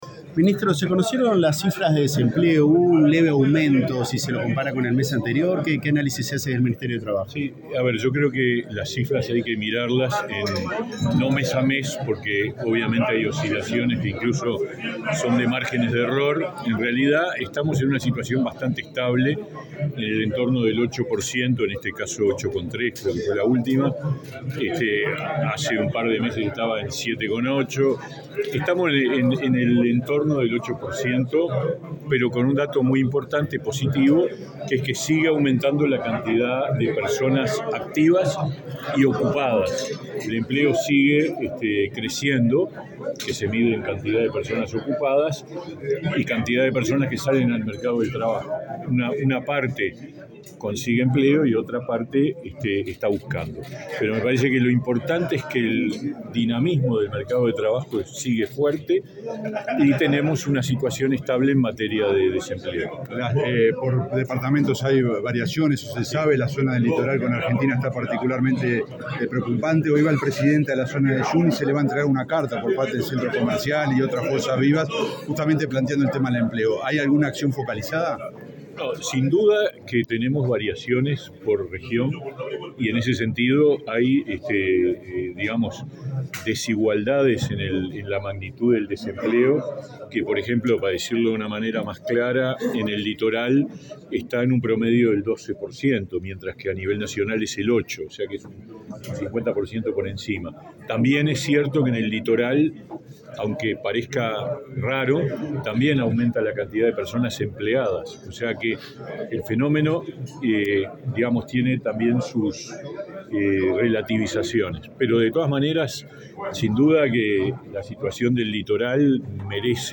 Declaraciones del ministro de Trabajo, Pablo Mieres
Declaraciones del ministro de Trabajo, Pablo Mieres 20/10/2023 Compartir Facebook X Copiar enlace WhatsApp LinkedIn Este viernes 20 en Montevideo, el ministro de Trabajo, Pablo Mieres, participó del lanzamiento de la campaña de prevención del uso de drogas en el ámbito laboral, en el sector de la construcción. Luego, dialogó con la prensa.